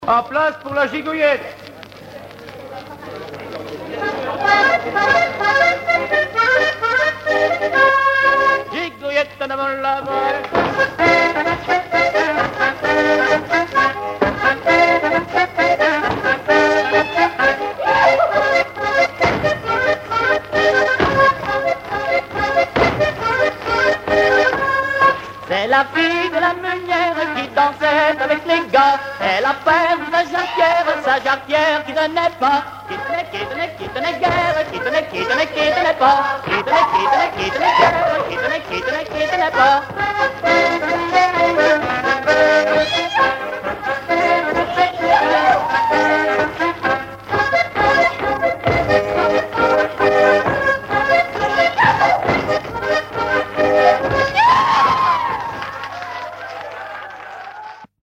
Chants brefs - A danser
danse : gigouillette
Pièce musicale inédite